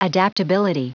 Prononciation du mot adaptability en anglais (fichier audio)
Prononciation du mot : adaptability